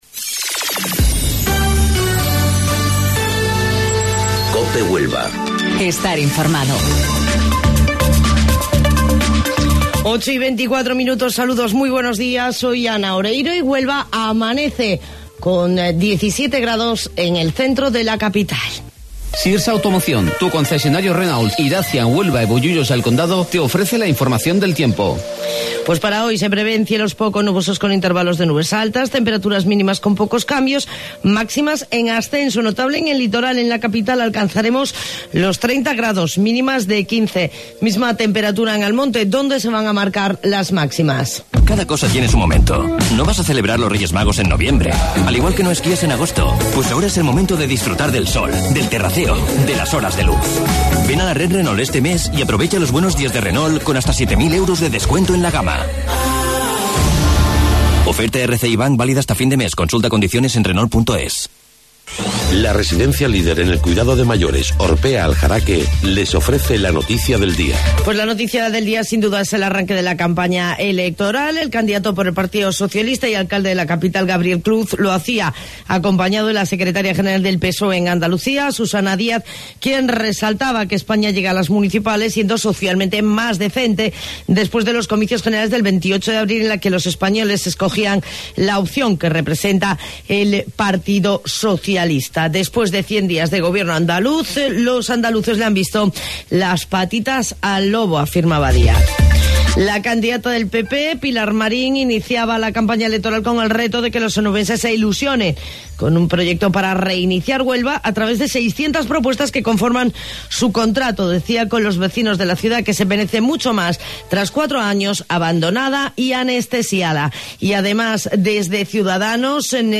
AUDIO: Informativo Local 08:25 del 10 de Mayo